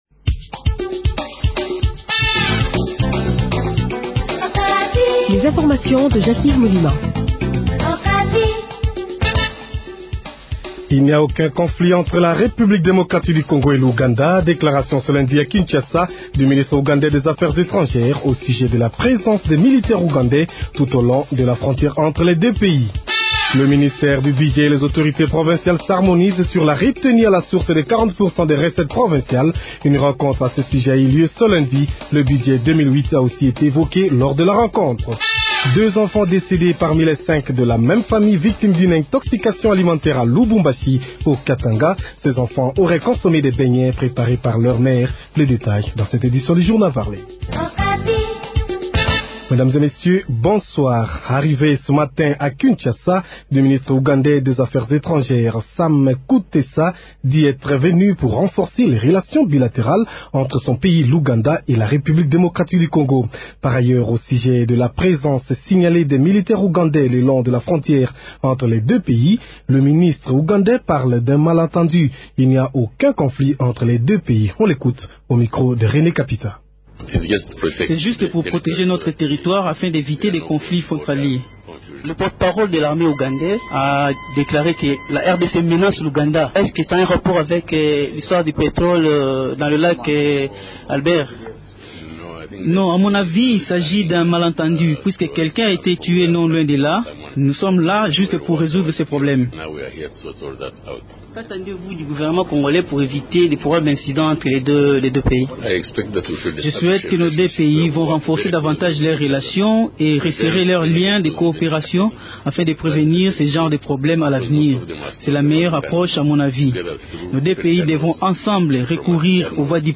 Journal Francais Soir